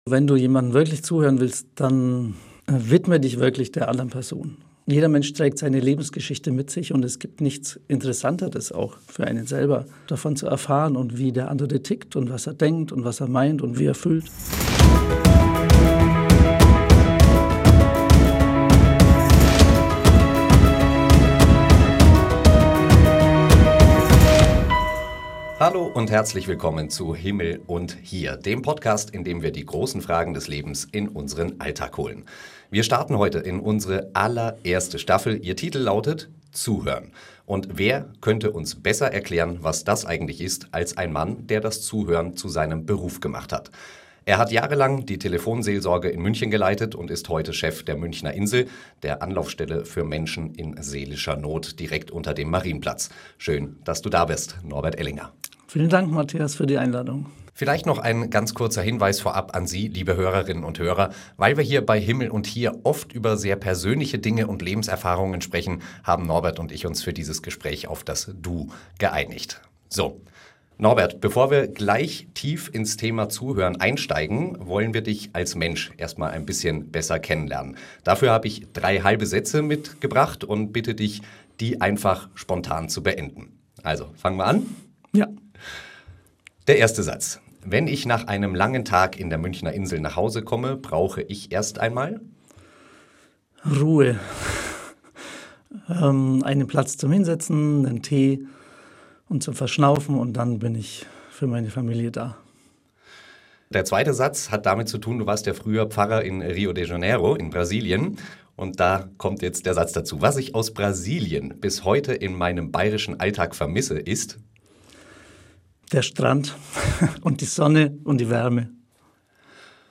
Ein Gespräch voller Wärme, bayerischem Charme und ganz konkreten Impulsen für unseren eigenen Alltag.